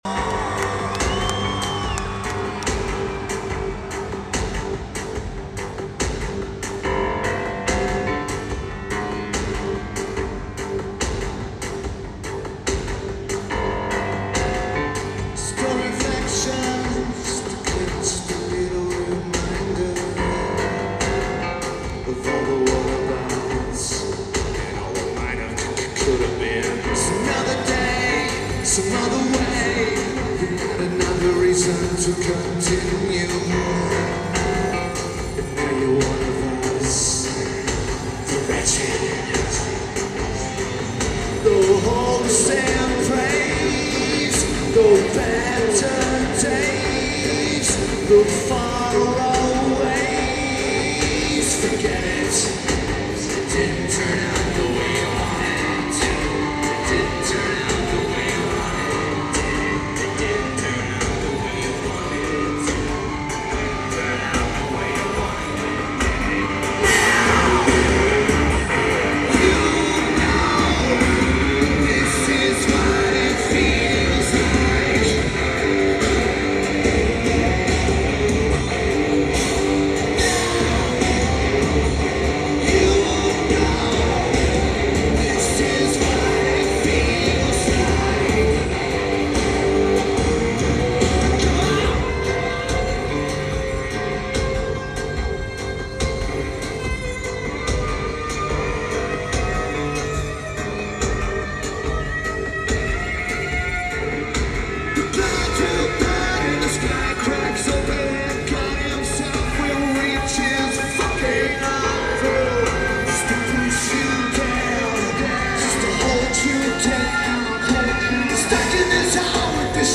Cox Arena
Lineage: Audio - AUD (Sony ECM-939LT + Sony TCD-D100)
This tape is average at best.